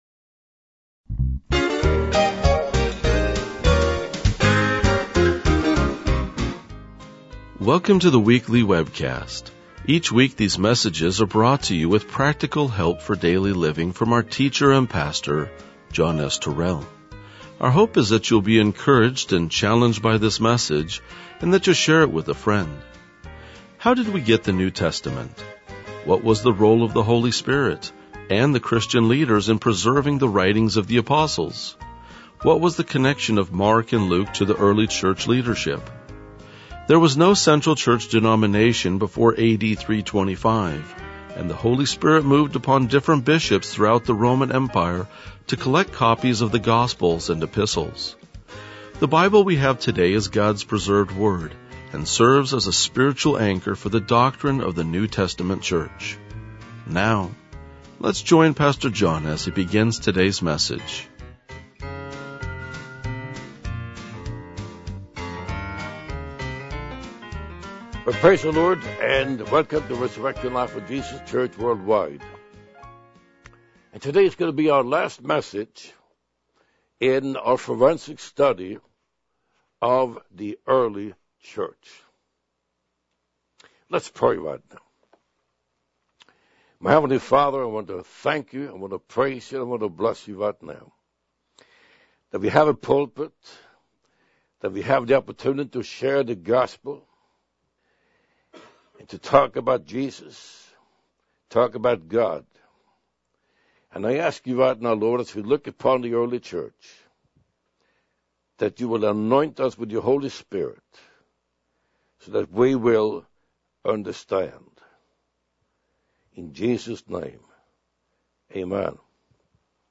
RLJ-2009-Sermon.mp3